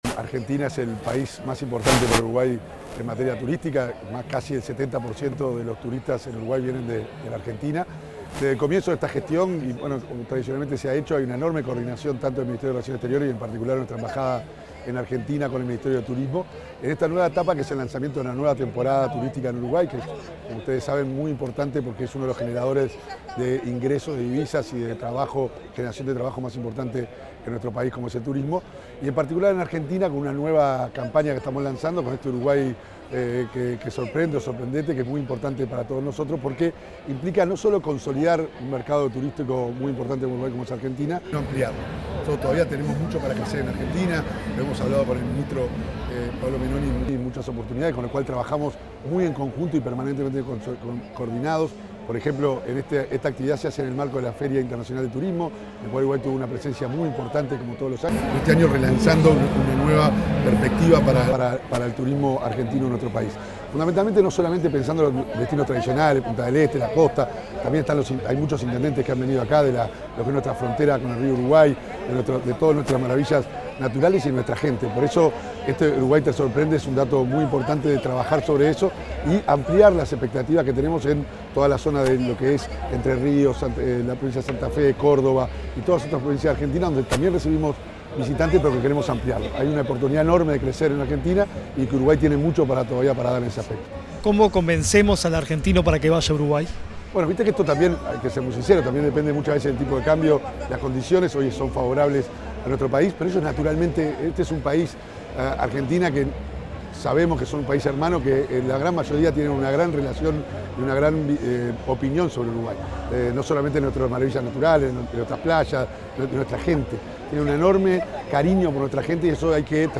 Declaraciones del embajador Diego Cánepa
Declaraciones del embajador Diego Cánepa 30/09/2025 Compartir Facebook X Copiar enlace WhatsApp LinkedIn El embajador de Uruguay en Argentina, Diego Cánepa, se expresó tras el lanzamiento de la campaña Uruguay Sorprende, en la Feria Internacional de Turismo, en Buenos Aires.